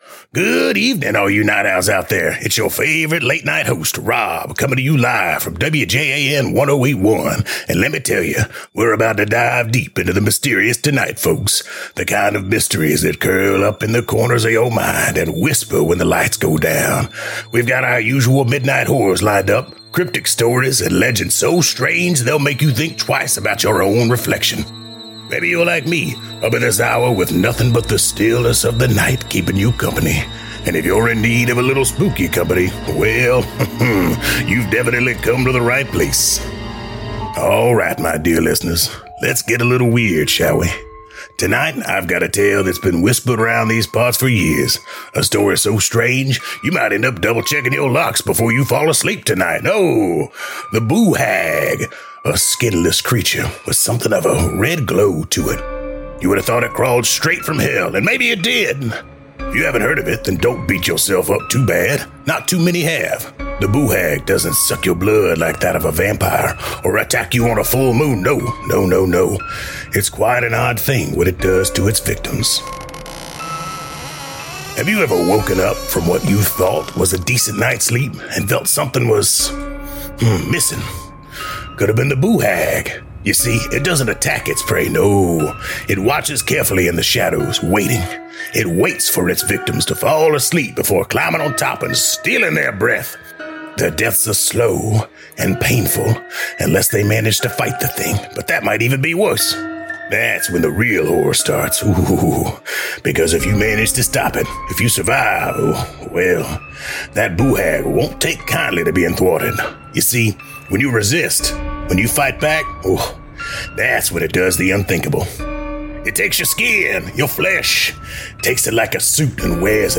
Tales from the Janitor: Chilling Urban Legends from South Carolina | Horror Audio Drama
Welcome to Tales from the Janitor — a horror podcast and immersive audio drama uncovering South Carolina urban legends, haunted Lowcountry folklore, and real paranormal nightmares locals would rather blame on humidity.